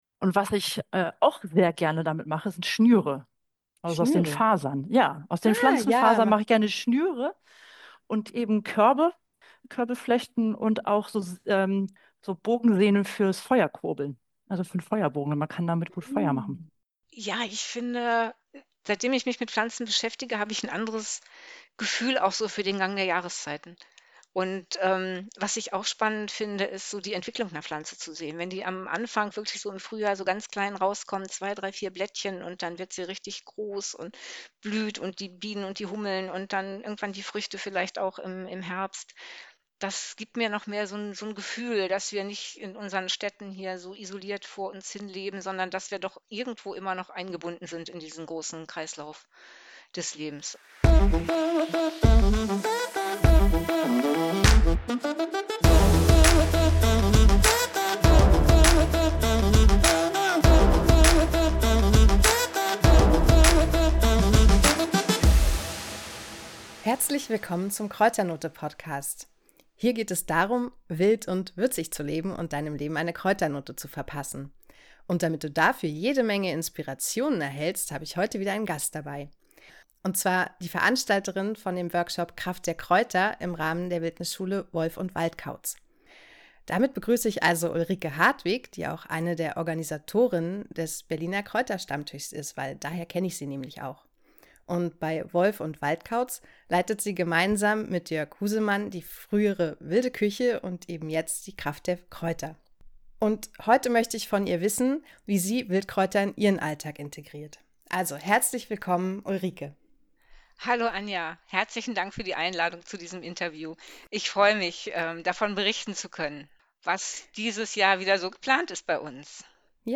Bitte wundere dich nicht: da es zeitlich nicht möglich war, das Interview gleichzeitig mit beiden zu führen, bekommst du nun den Input von beiden nacheinander als Patchwork-Podcast zu hören.